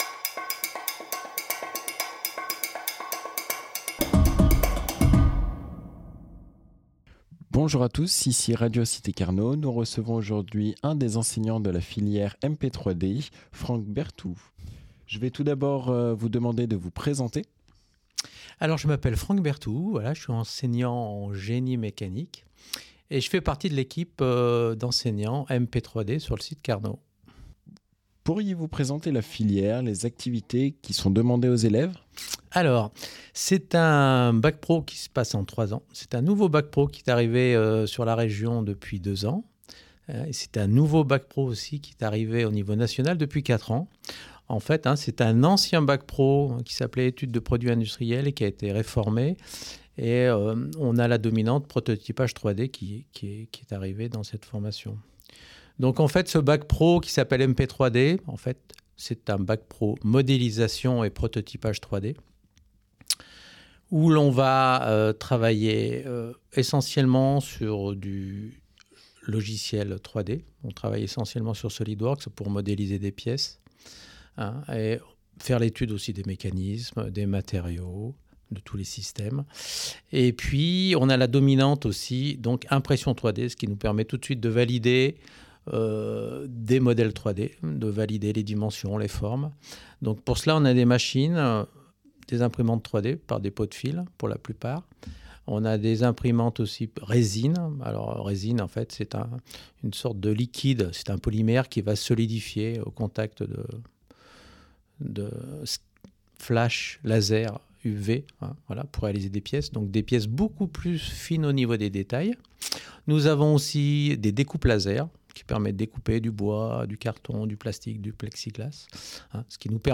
Interview MP3D - Webradio du lycée - Cité Scolaire Hippolyte Carnot